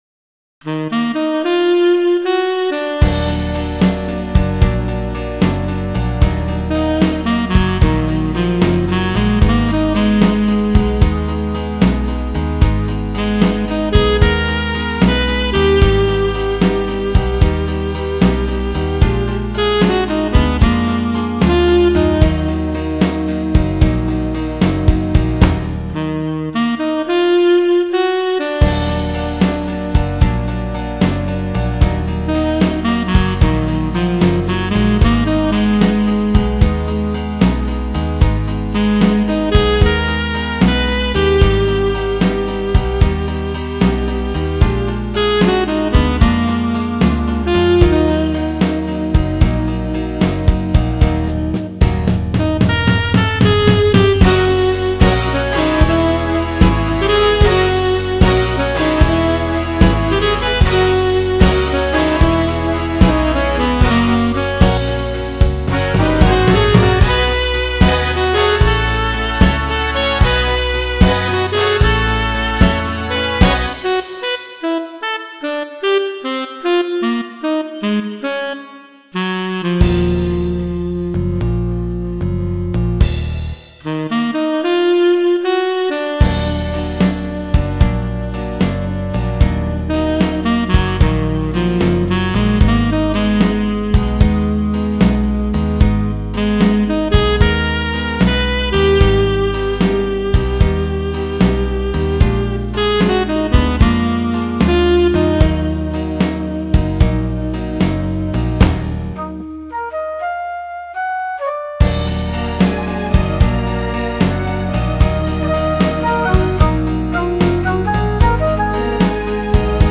jazz standard
Use these backups, mute the sax track, and go for it.